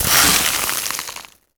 ice_spell_freeze_frost_01.wav